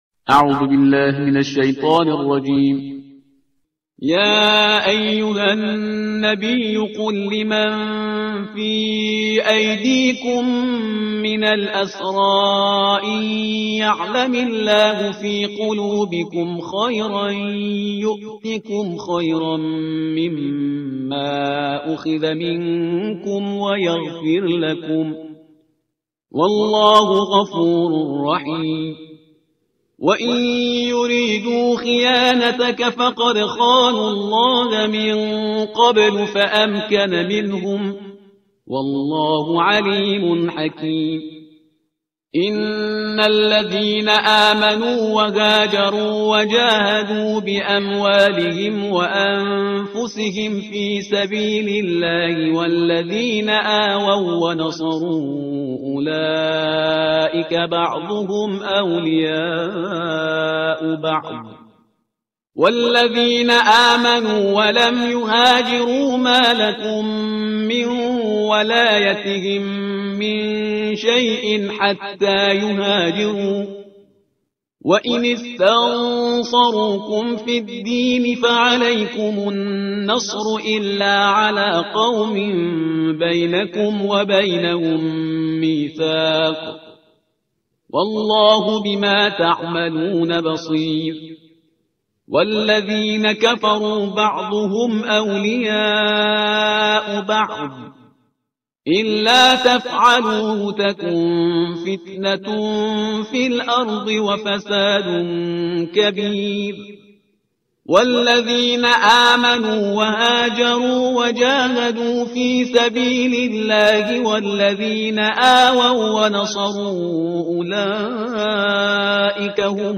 ترتیل صفحه 186 قرآن